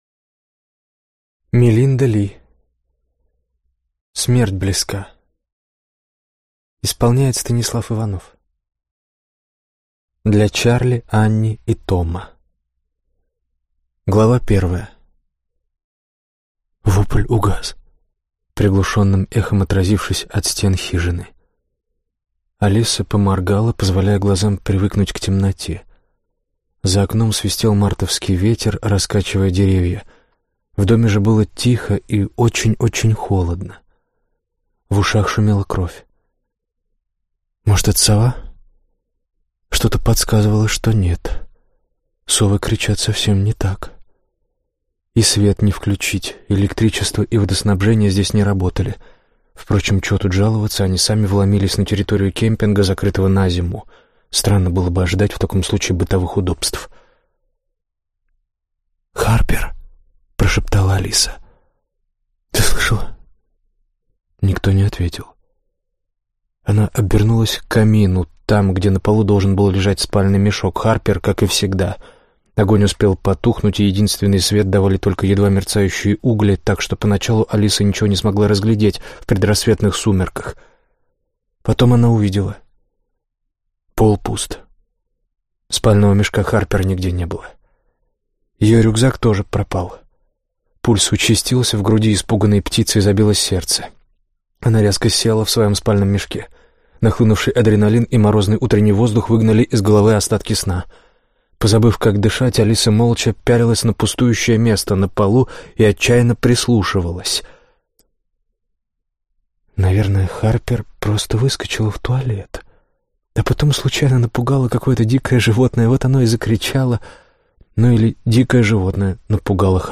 Аудиокнига Смерть близка | Библиотека аудиокниг